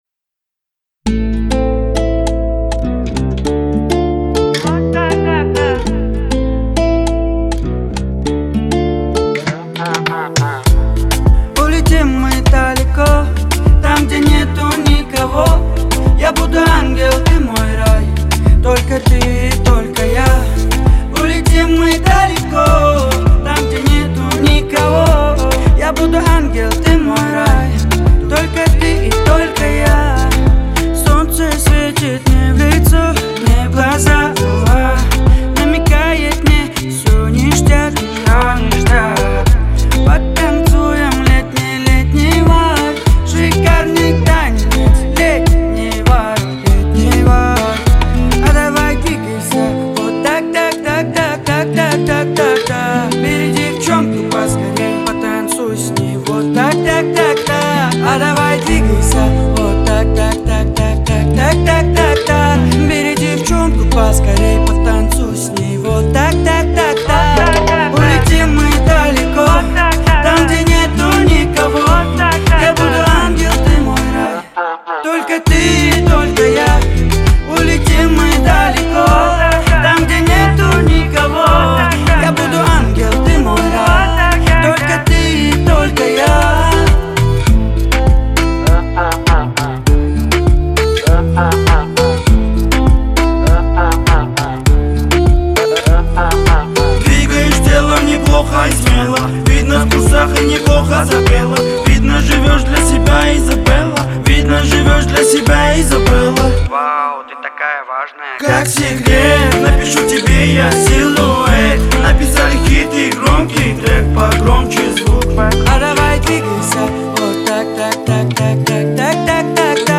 Поп музыка